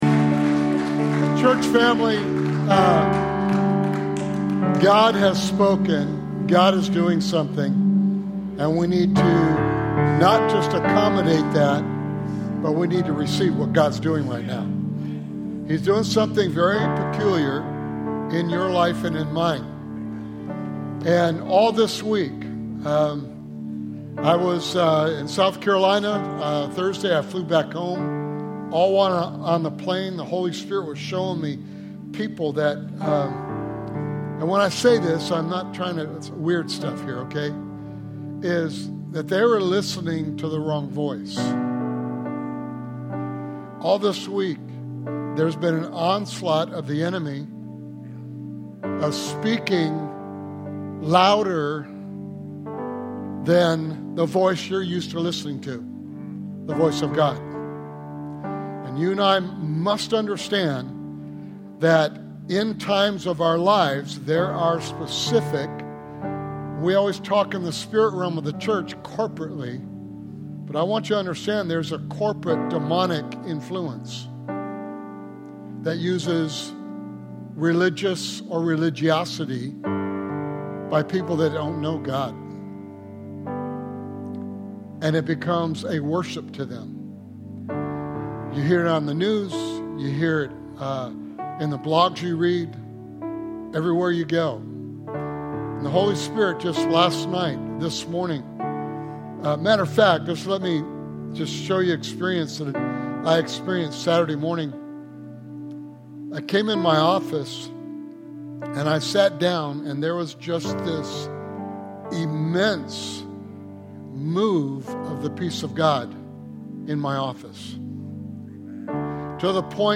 Sermon Series: God Given Benefits/Gifts